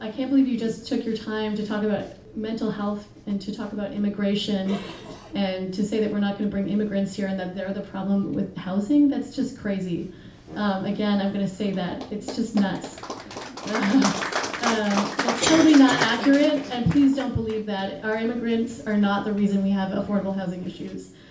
A large group of citizens at St. Joseph’s Parish was engaged in the discussion for close to two hours.